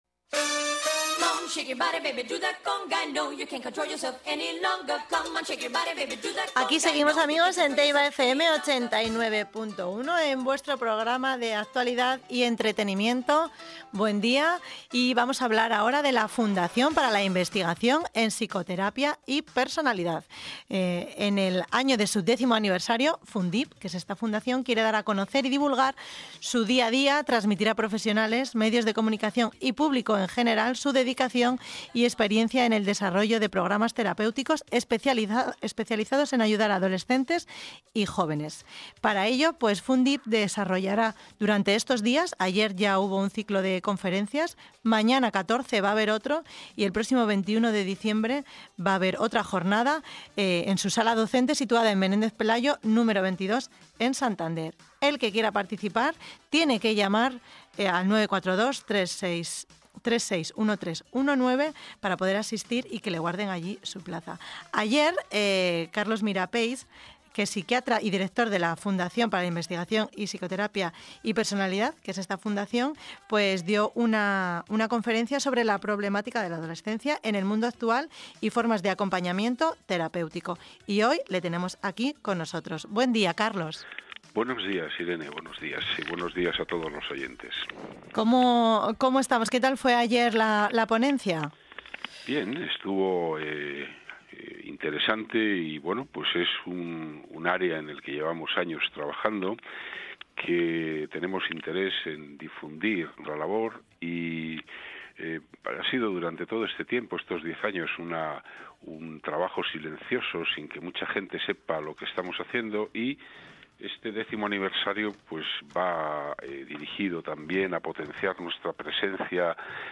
Entrevista radio - Fundación para la investigación en psicoterapia y personalidad